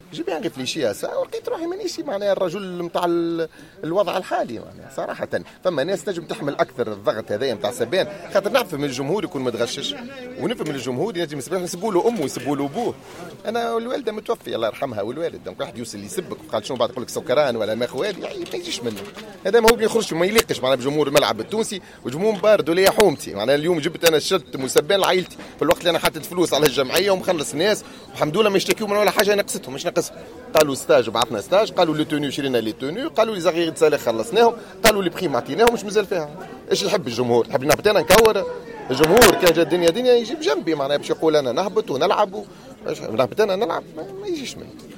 تصريح لجوهرة اف ام